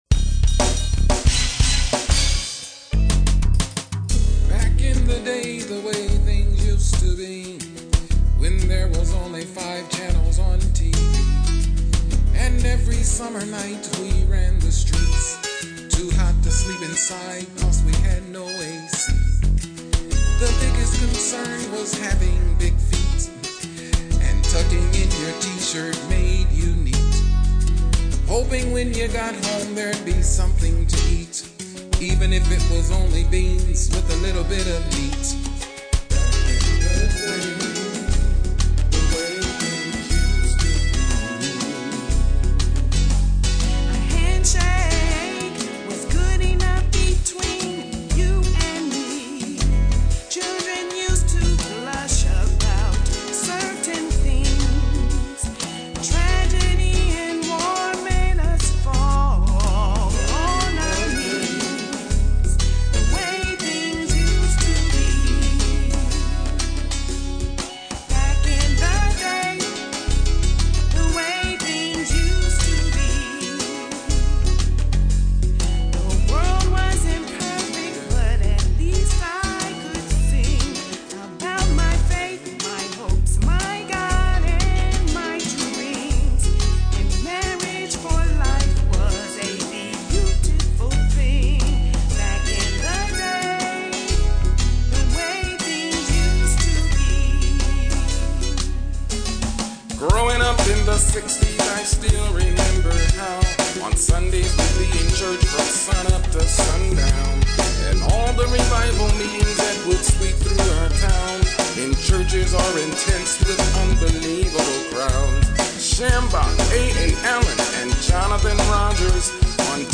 uniquely, simplistic style